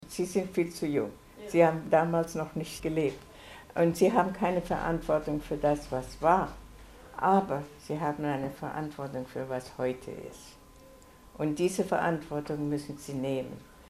Die Gelegenheit, der Rednerin nach ihren Ausführungen noch Fragen zu stellen, wurde von den Jugendlichen ausgiebig in Anspruch genommen.